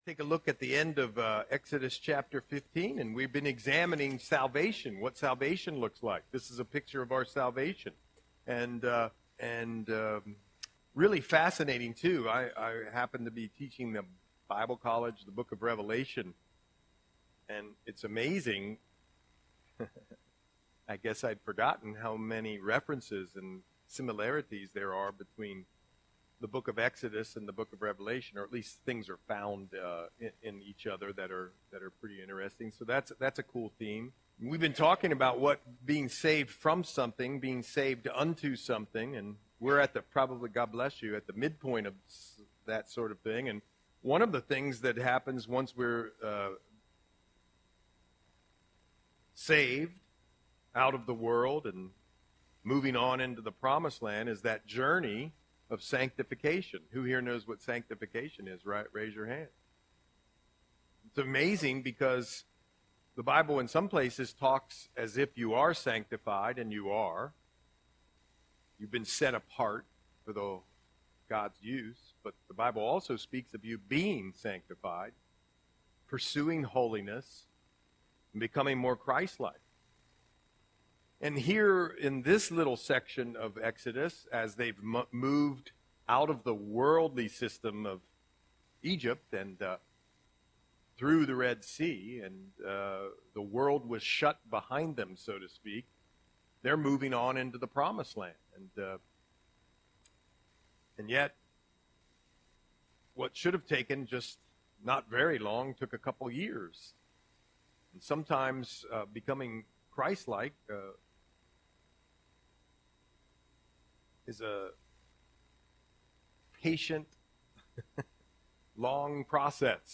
Audio Sermon - February 5, 2025